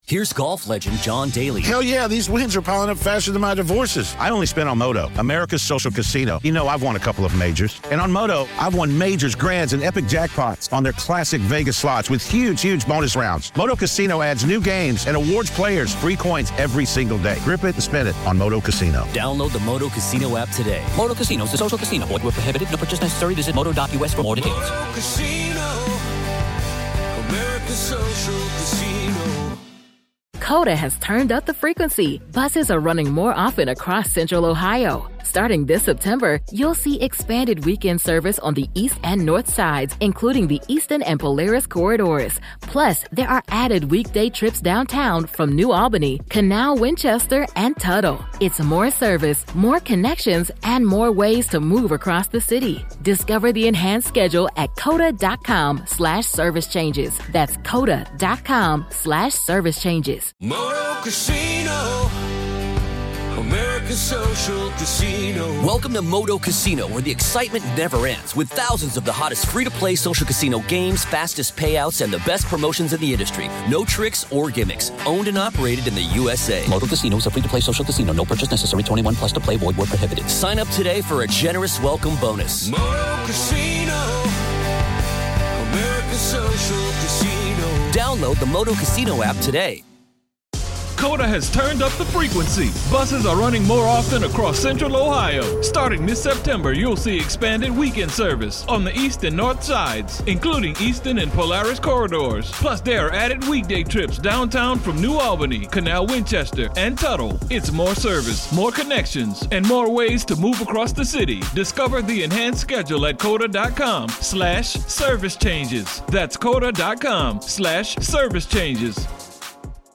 Join me for a fascinating conversation